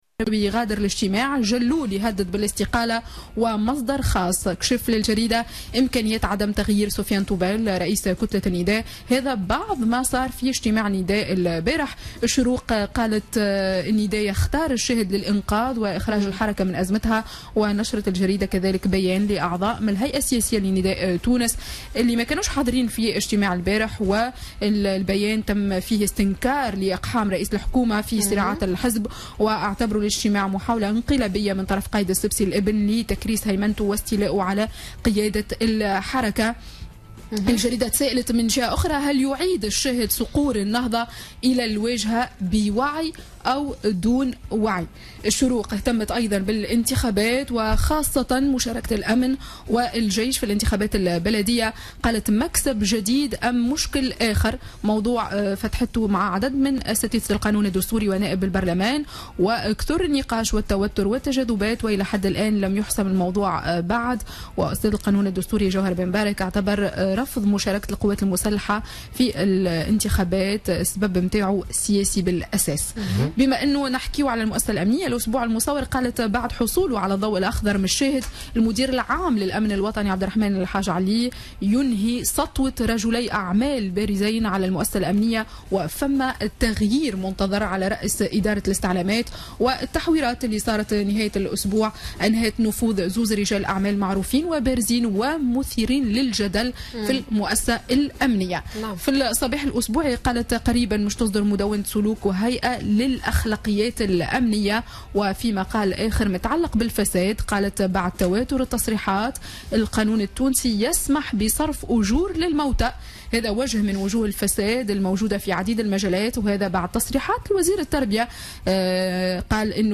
Revue de presse du lundi 19 septembre 2016